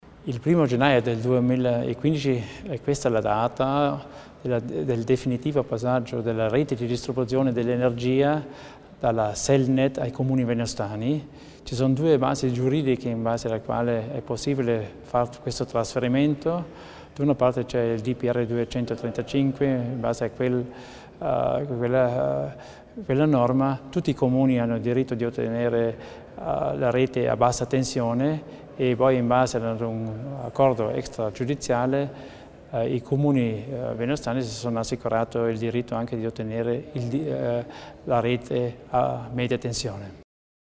L'Assessore Theiner illustra le novità in tema di energia in Val Venosta